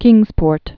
(kĭngzpôrt)